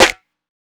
Start It Up Snare.wav